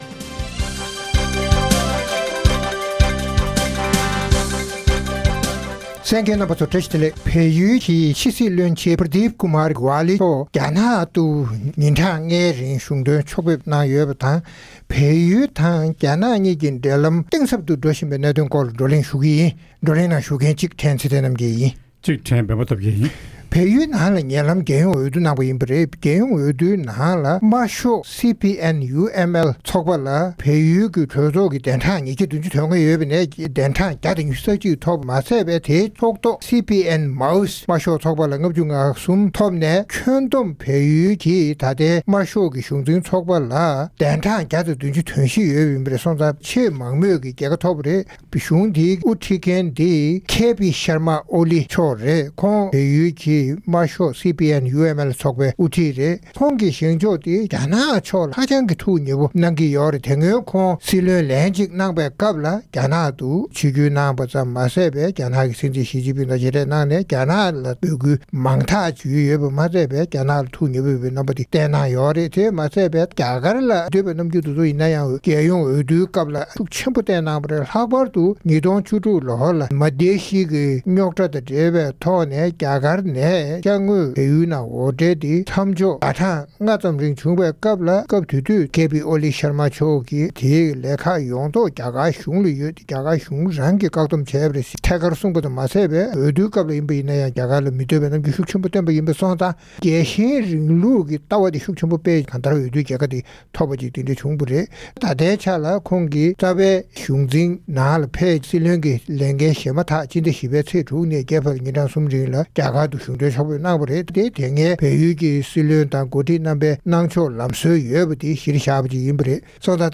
བལ་ཡུལ་གྱི་ཕྱི་སྲིད་བློན་ཆེན་ Pradeep Kumar Gyawali མཆོག་ཉེ་ལམ་རྒྱ་ནག་ཏུ་གཞུང་དོན་ཕྱོགས་ཕེབས་གནང་སྟེ་རྒྱ་ནག་གི་ཕྱི་སྲིད་བློན་ཆེན་ཝང་དབྱི་དང་མཇལ་འཛོམས་གནང་སྟེ་ཡུལ་གྲུ་གཉིས་དབར་འབྲེལ་ལམ་གཏིང་ཟབ་ཏུ་གཏོང་ཕྱོགས་སྤྱི་དང་།བྱེ་བྲག་ཏུ་བོད་ཀྱི་གྲོང་ཁྱེར་གཞིས་ཀ་རྩེ་ནས་བལ་ཡུལ་གྱི་རྒྱལ་ས་Kathmandu བར་འགྲུལ་བཞུད་མེ་འཁོར་ལྕགས་ལམ་འདིང་རྒྱུའི་ལས་གཞི་གྲོས་མོལ་གནང་བ་སོགས་ཀྱི་སྐོར་རྩོམ་སྒྲིག་འགན་འཛིན་རྣམ་པས་བགྲོ་གླེང་གནང་བ་ཞིག་གསན་རོགས་གནང་།།